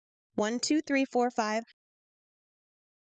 4666a22b-2c1b-42e9-951b-ab241a374b83 For this call I do hear one two three four five